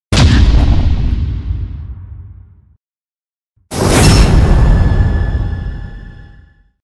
На этой странице собраны звуки души — необычные аудиокомпозиции, отражающие тонкие эмоциональные состояния.